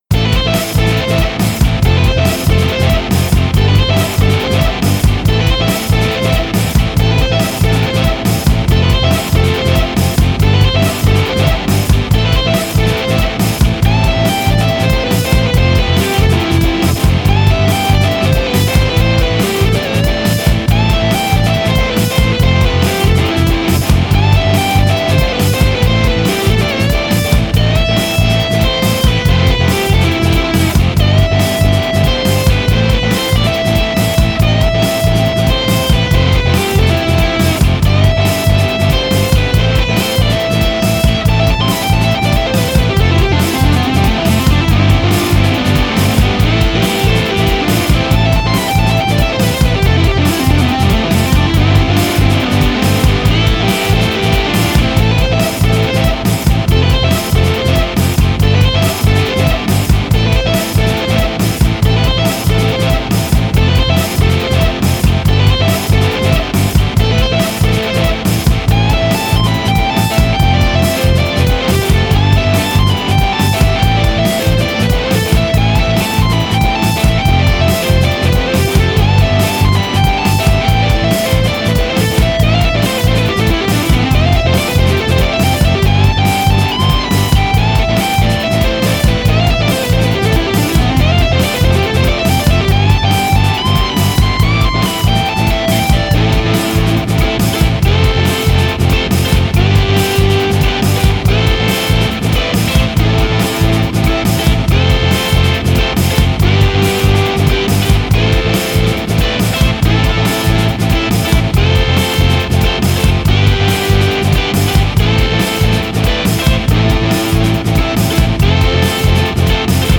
ハーモニカがメインのロカビリー風ブルースです。 [BPM:120]